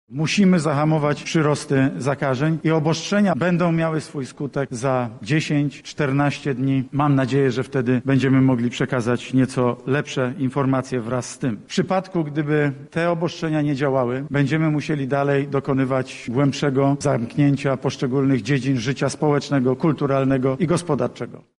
-mówi prezes Rady Ministrów Mateusz Morawiecki.